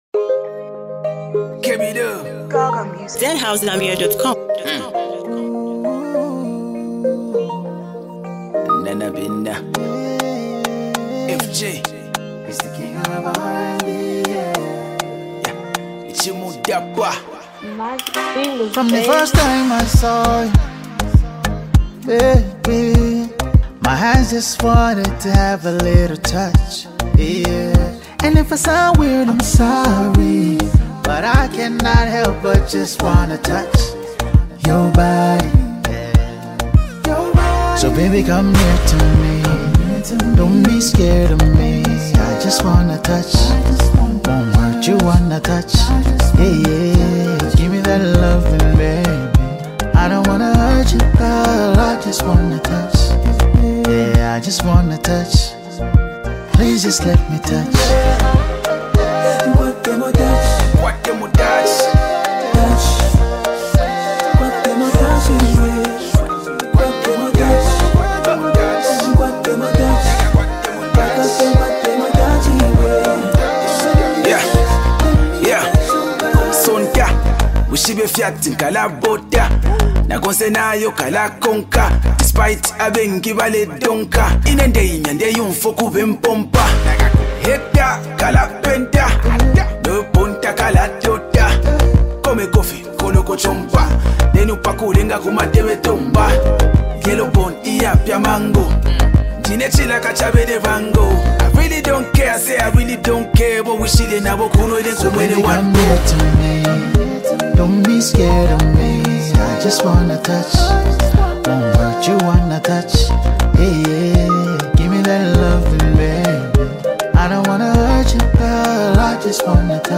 soulful vocals
sharp verses